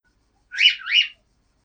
bip_03.wav